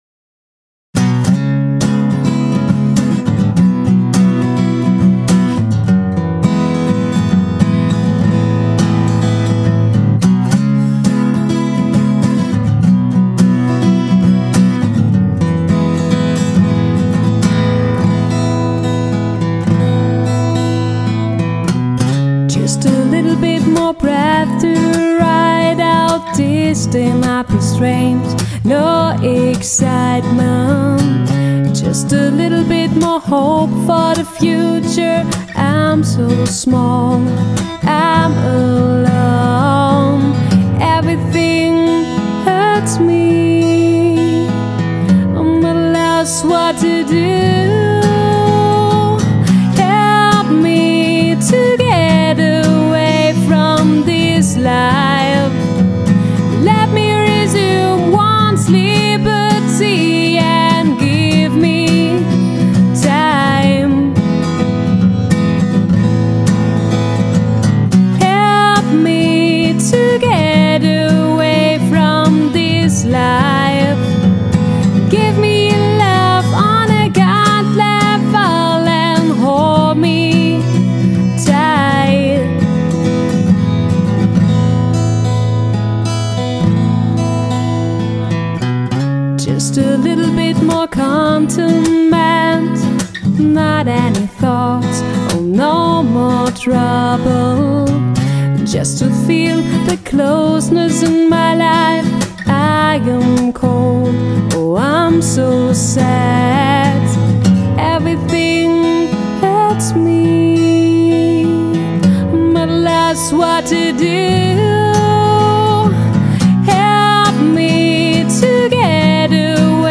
• Genre: Acoustic